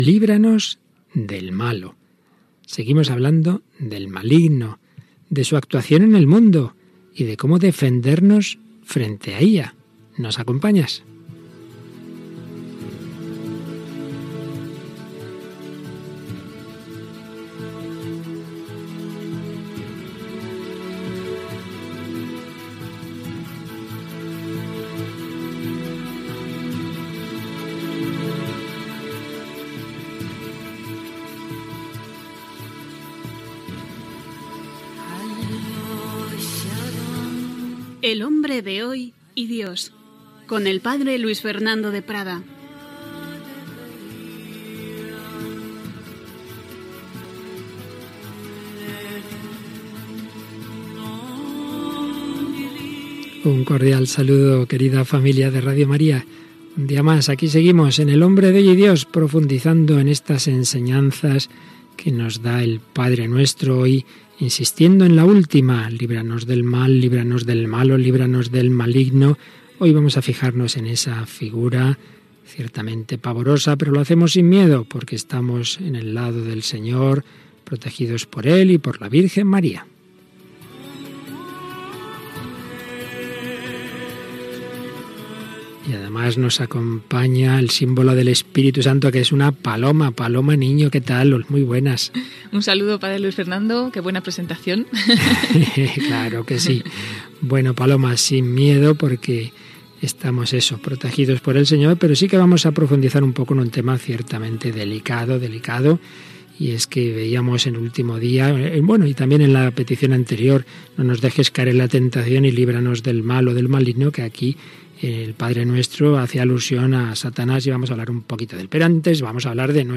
Careta i entrada del programa dedicat al maligne, agraint la participació de l’audiència.
Religió